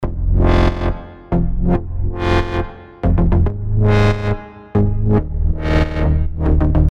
描述：我本来想说的是dubstep，但我把它放在Ambient类型中，因为我认为它不是真正的dubstep。
Tag: 140 bpm Ambient Loops Bass Loops 1.15 MB wav Key : Unknown